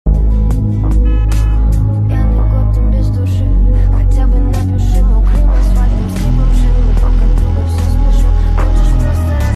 Master Car Driving in the sound effects free download
Master Car Driving in the Urban Jungle!